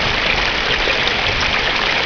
water6.wav